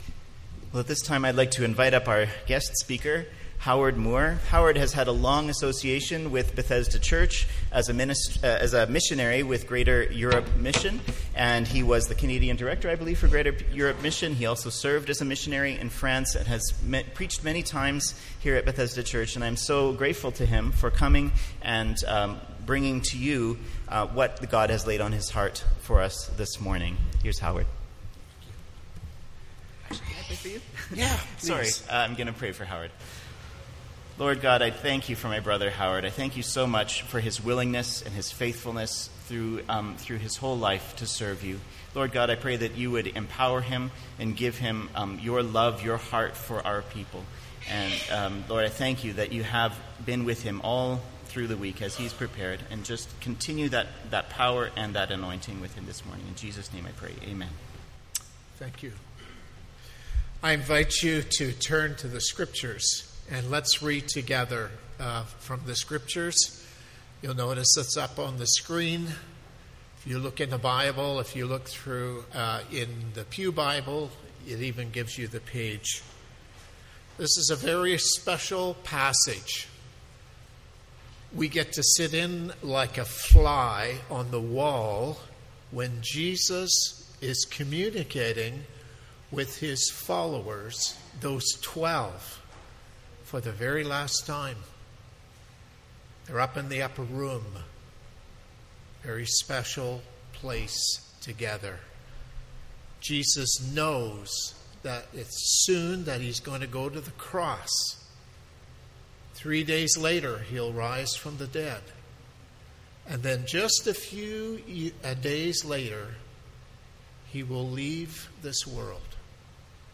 MP3 File Size: 29.2 MB Listen to Sermon: Download/Play Sermon MP3